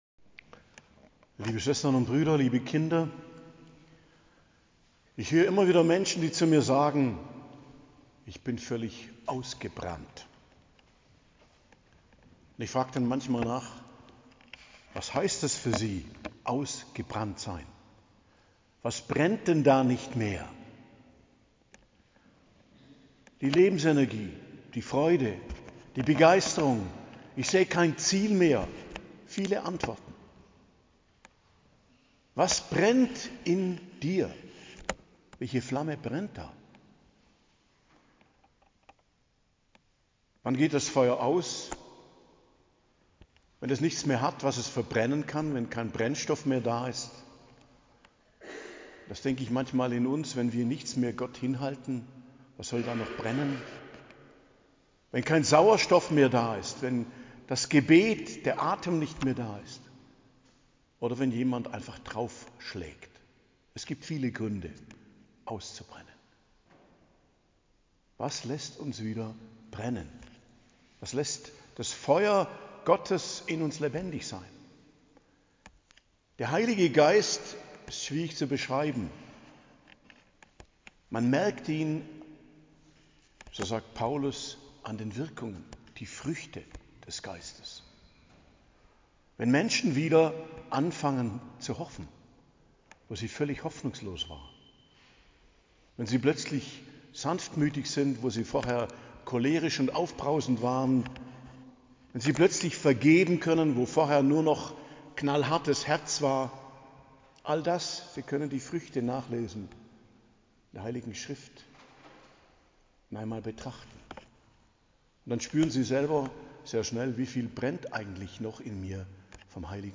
Predigt zum Pfingstsonntag, 8.06.2025 ~ Geistliches Zentrum Kloster Heiligkreuztal Podcast